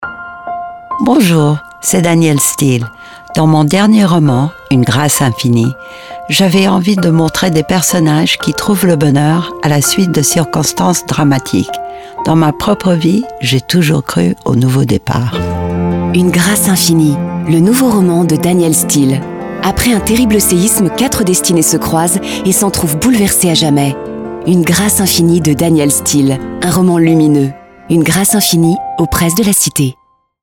Télécharger le fichier spot_radio